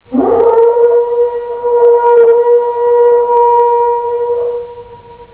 ホラ貝(WAV)(約42k)PCM音源（以前のはｻｳﾝﾄﾞﾌﾞﾗｽﾀでないと再生が難しいようだったので、補正したつもり。）
hora.wav